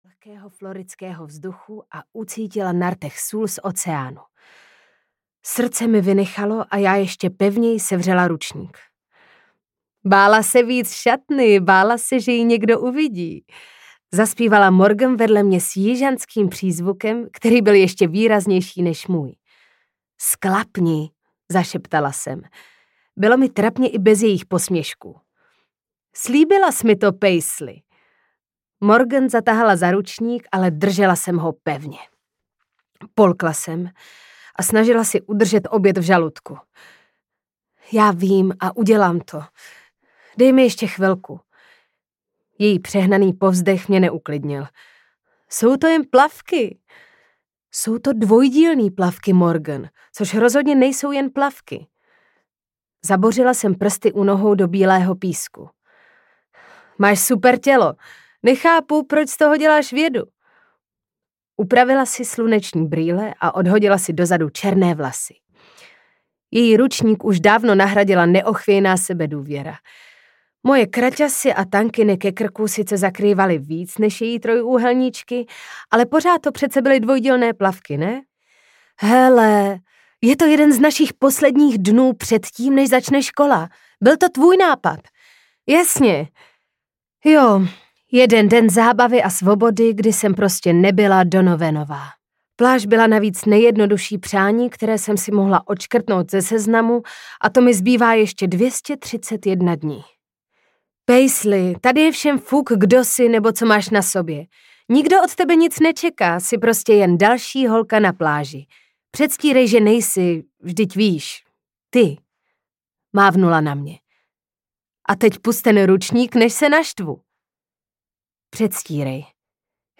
Vzhlédnout k nebi audiokniha
Ukázka z knihy